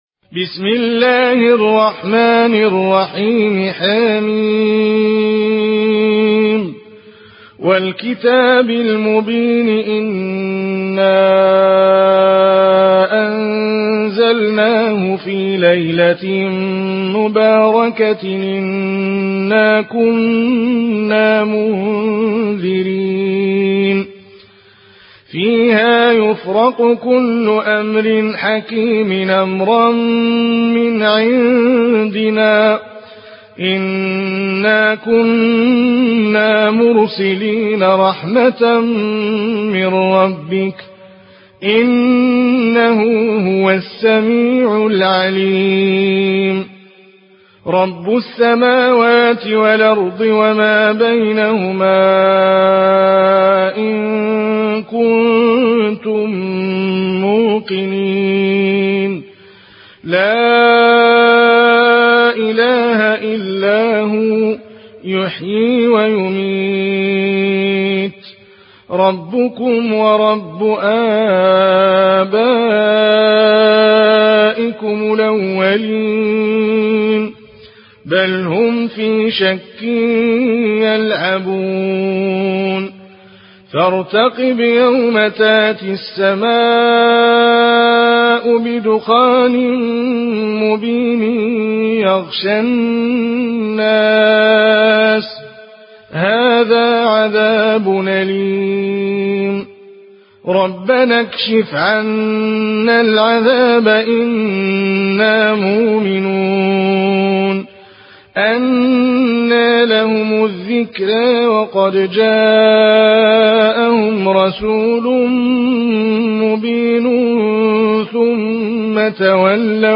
مرتل ورش عن نافع